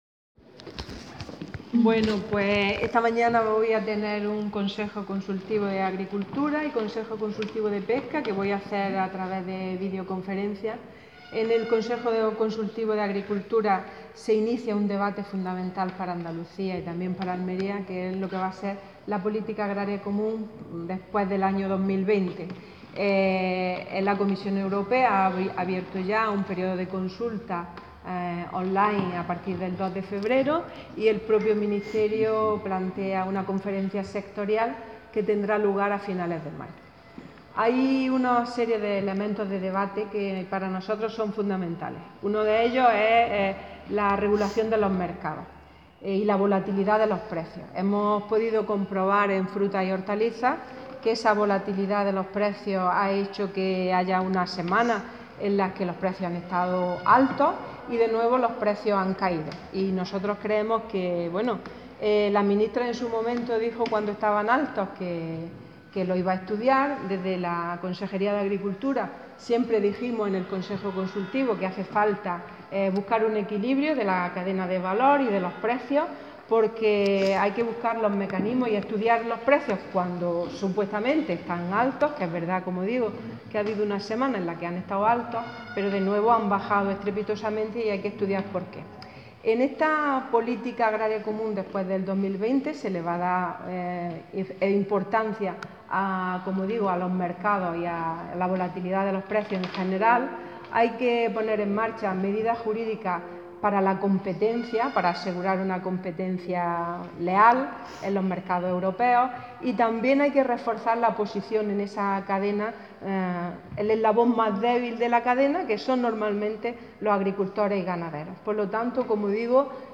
Declaraciones consejera sobre consultivos